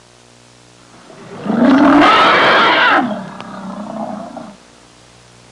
Growling Elephant Sound Effect
Download a high-quality growling elephant sound effect.
growling-elephant.mp3